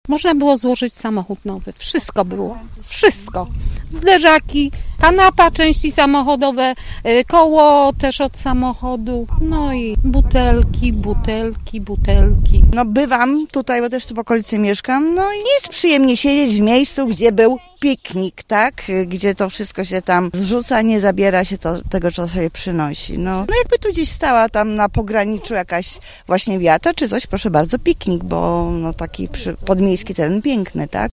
Jak mówią uczestniczki akcji, nie jest przyjemnie spędzać czas wśród czyichś odpadów, a tych znalazły na terenie Muraw naprawdę sporo: